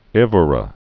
(ĕv-rə)